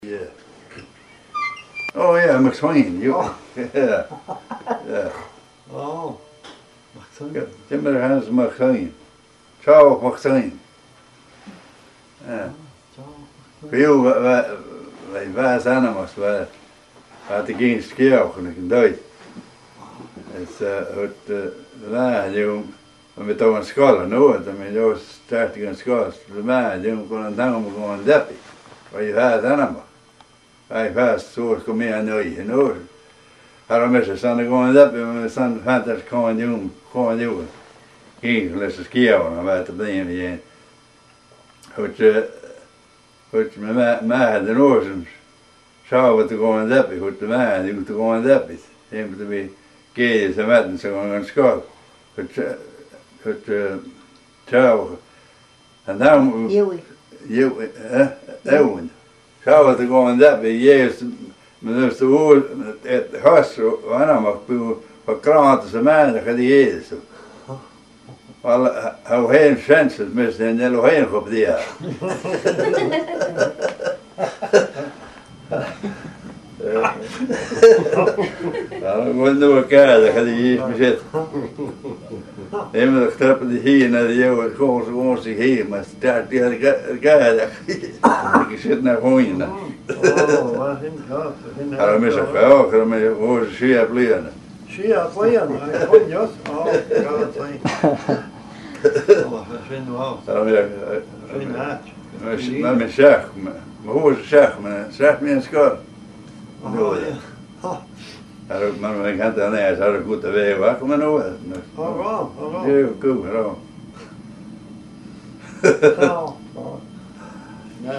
An Neach-agallaimh